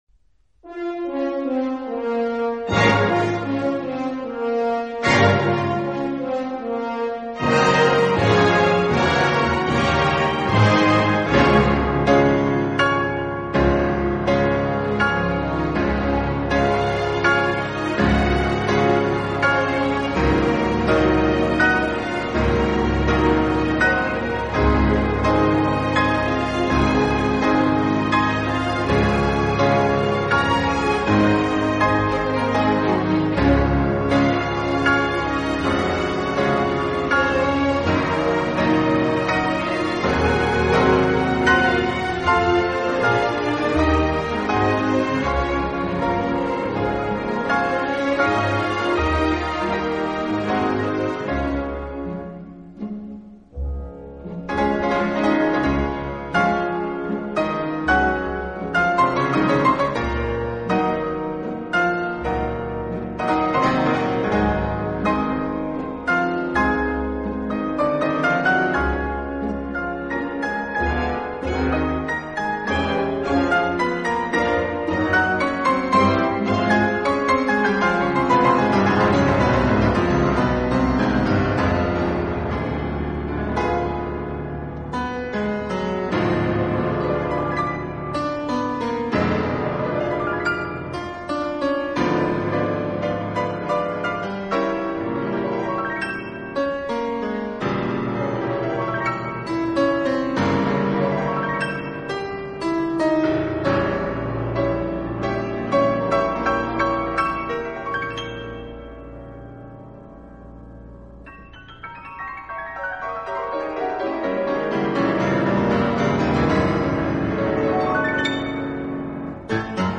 音乐流派：Classical